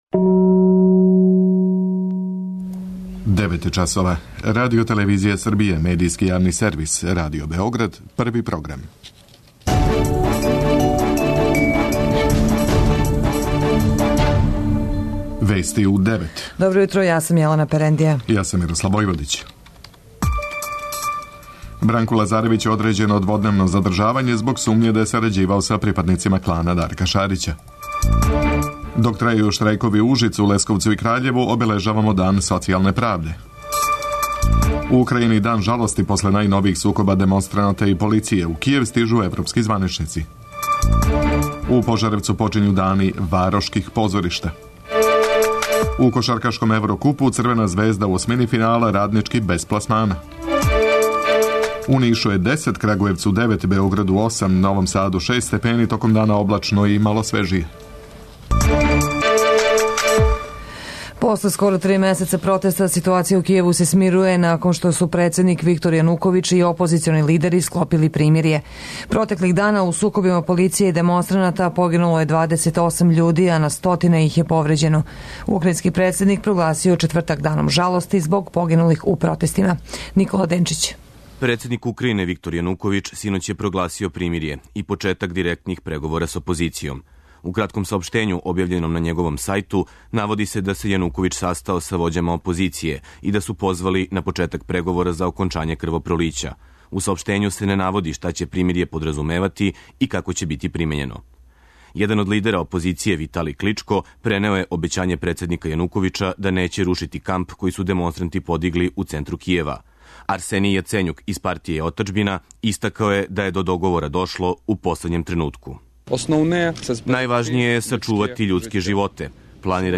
Уредници и водитељи
преузми : 10.16 MB Вести у 9 Autor: разни аутори Преглед најважнијиx информација из земље из света.